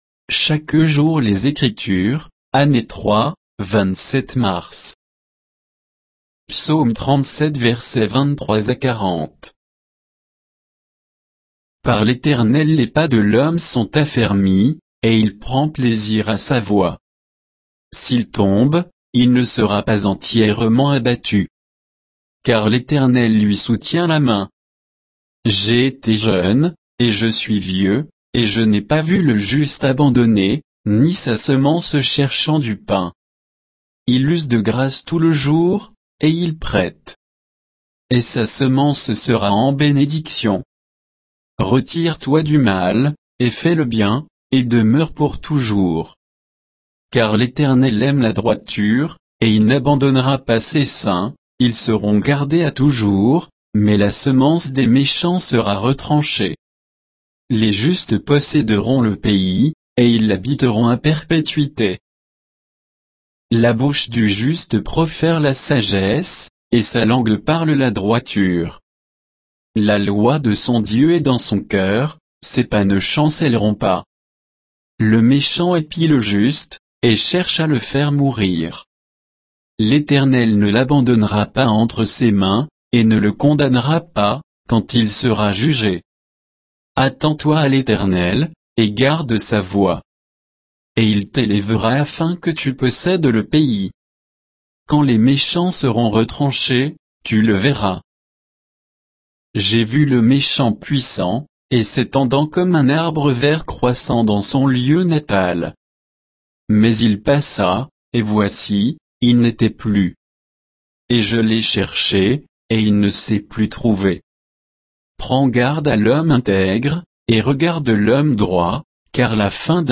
Méditation quoditienne de Chaque jour les Écritures sur Psaume 37, 23 à 40